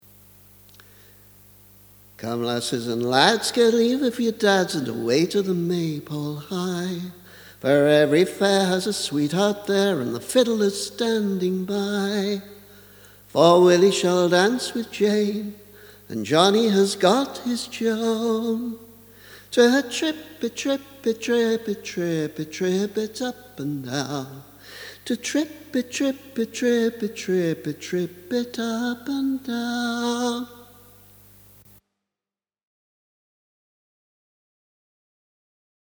Come lasses and lads [demo]
Not a song I'm likely to record seriously, just a rough version of the first verse as an illustration for an article I wrote for another blog.